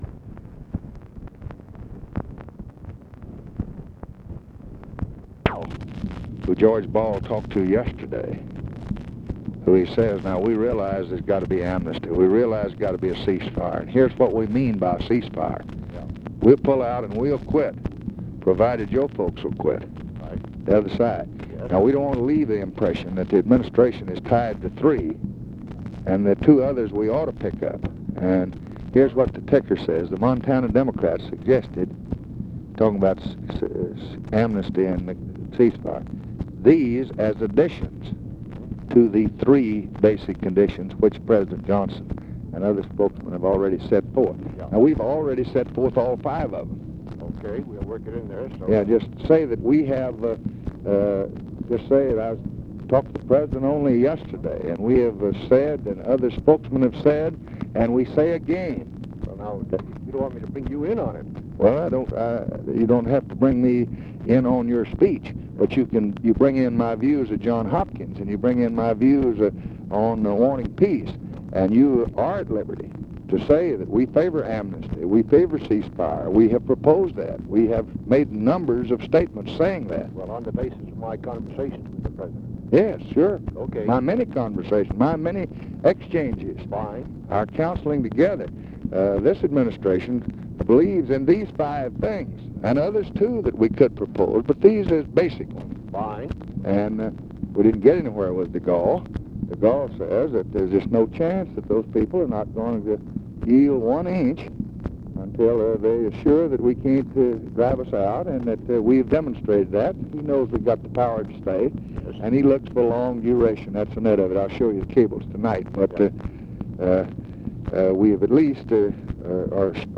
Conversation with MIKE MANSFIELD, September 1, 1965